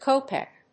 /kóʊpek(米国英語), kˈəʊpek(英国英語)/